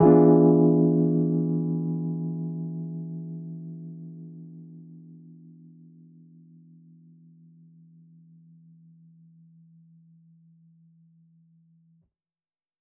JK_ElPiano3_Chord-E7b9.wav